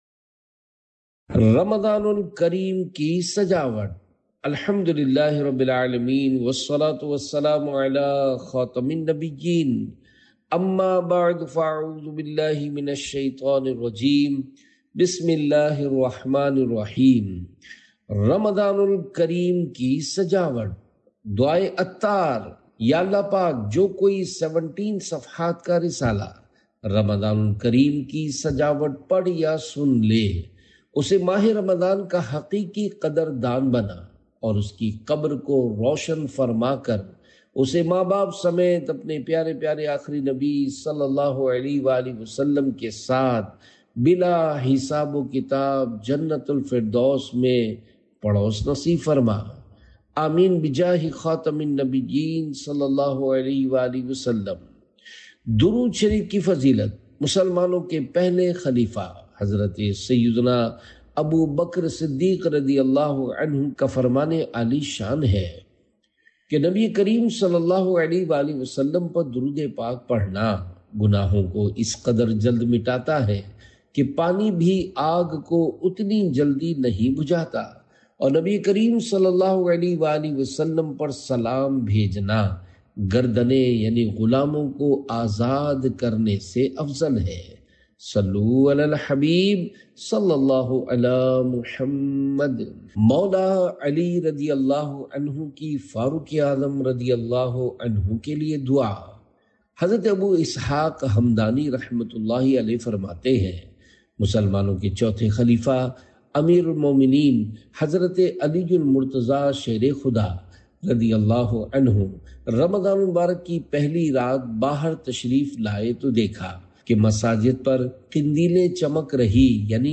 Audiobook - Ramazan Ul Kareem Ki Sajawat (Urdu)